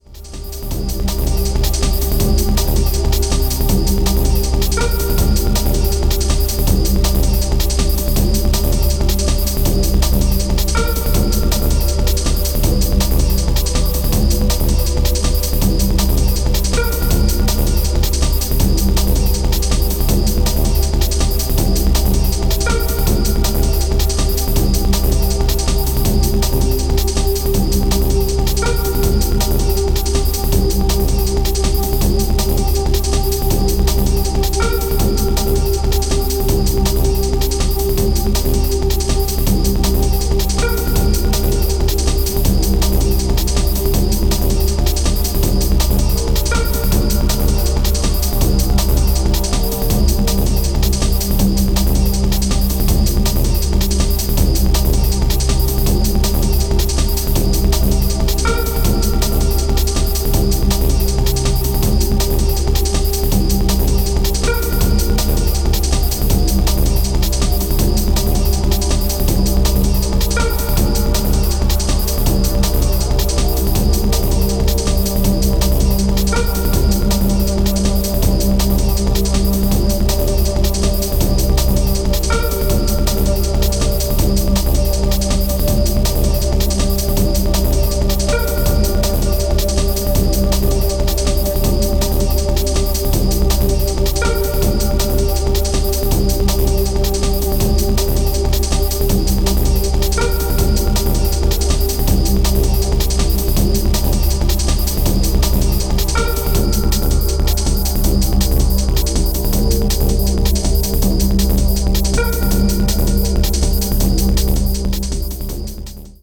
Techno Bass